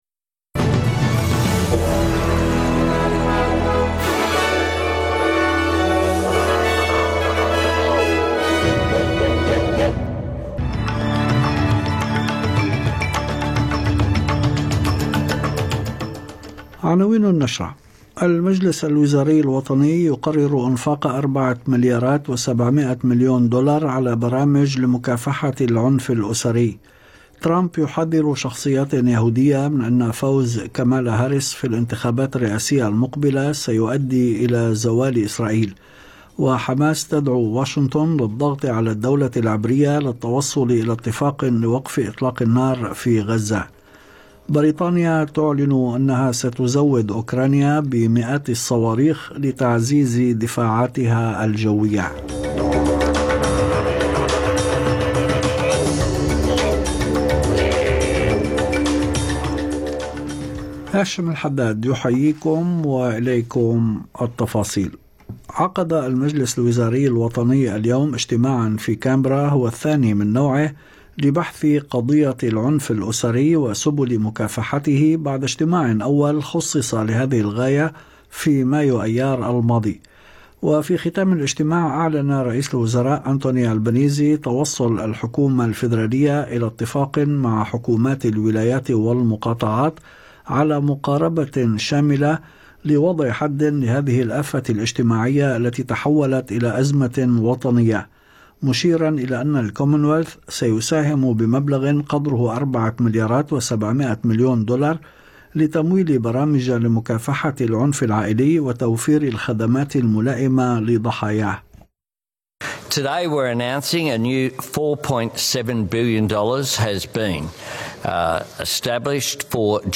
نشرة أخبار المساء 6/9/2024